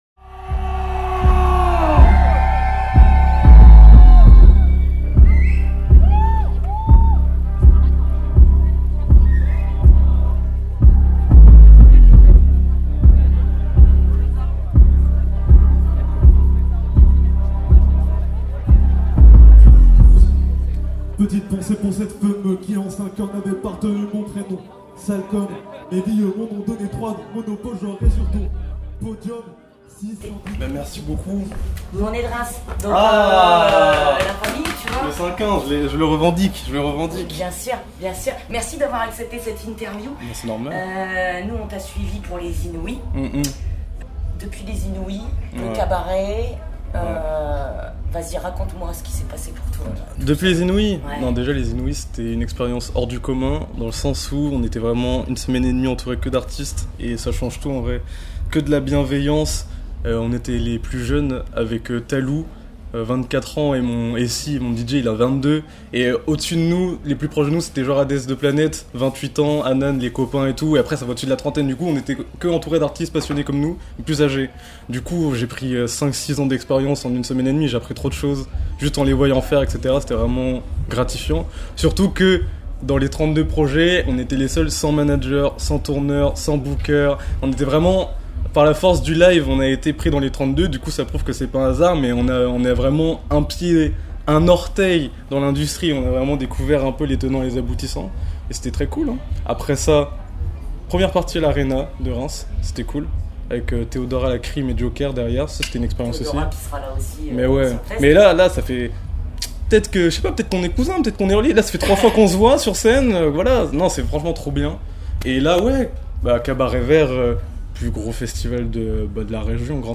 On les a croisé·es sur le Cabaret Vert 2025